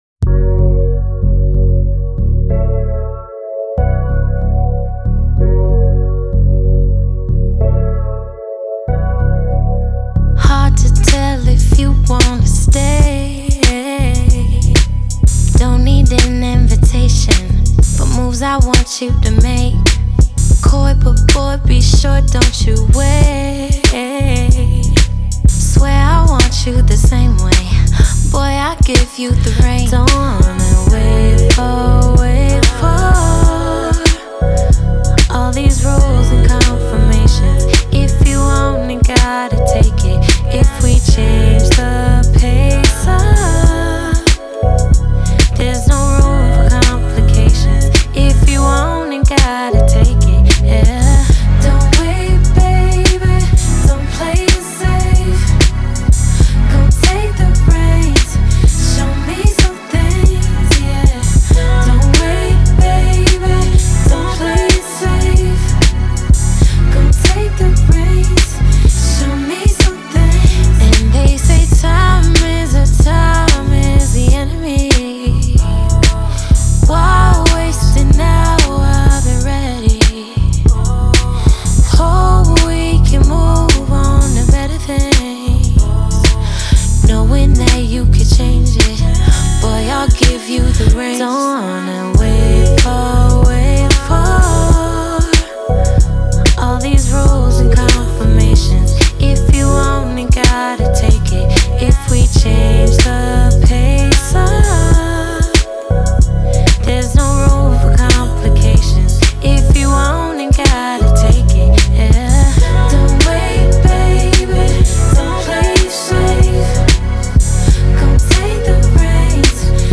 SINGLESR&B/SOUL